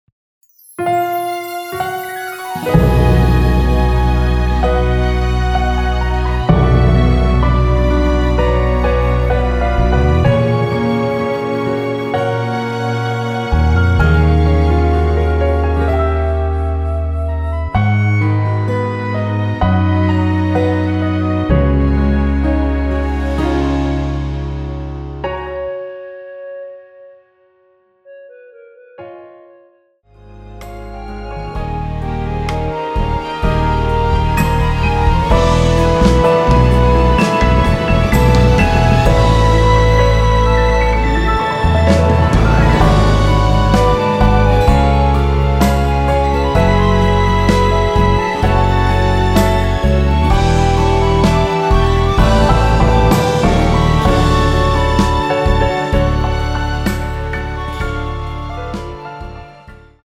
여자키 멜로디 포함된 MR 입니다.(미리듣기 참조)
F#
앞부분30초, 뒷부분30초씩 편집해서 올려 드리고 있습니다.
중간에 음이 끈어지고 다시 나오는 이유는